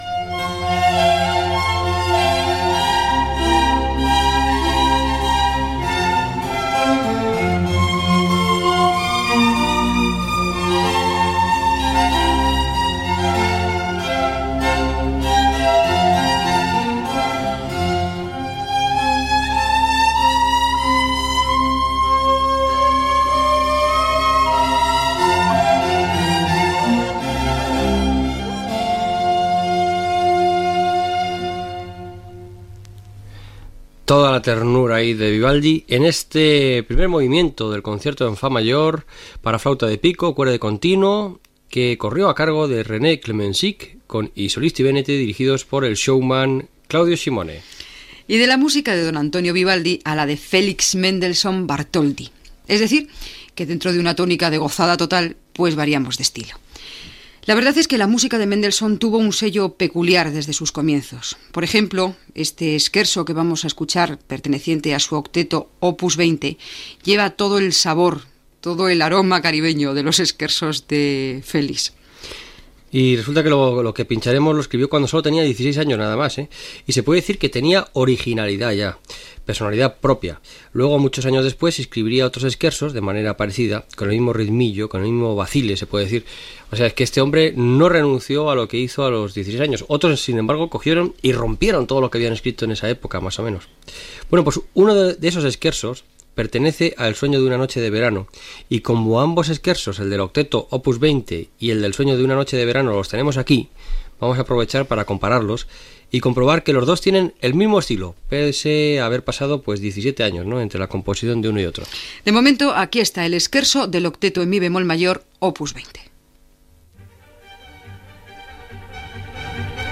Tema musical, comentari i presentació del següent tema
Musical